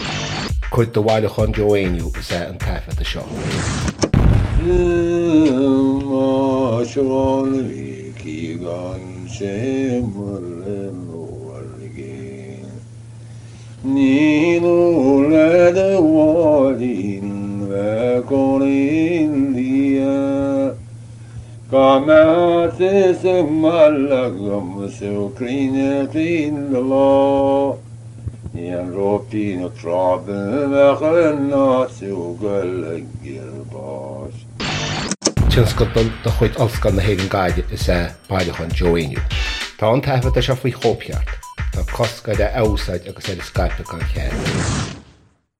• Catagóir (Category): song.
• Ainm an té a thug (Name of Informant): Joe Heaney.
• Suíomh an taifeadta (Recording Location): Wesleyan University, Middletown, Connecticut, United States of America.
This fragment is included in order to illustrate the local air to this well-known Conamara song.